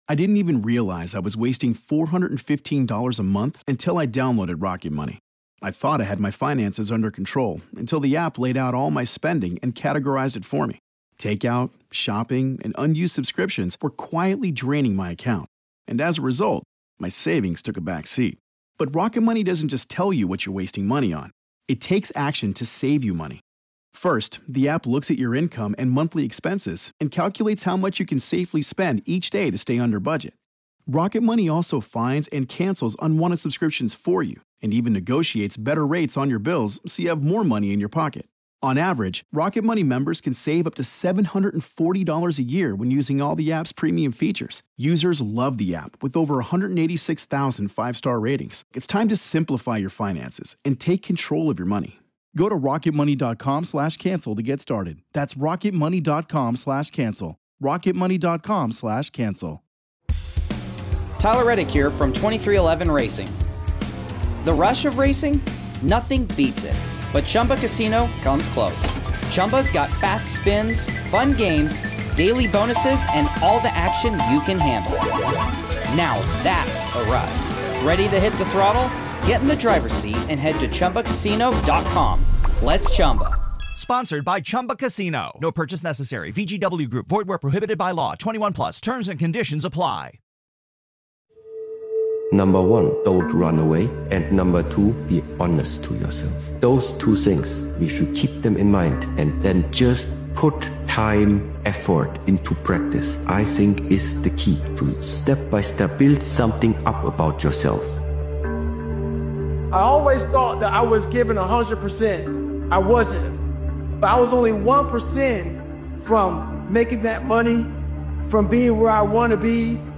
This upgraded motivational speeches compilation reinforces the truth that emotions are unreliable, but structure creates results.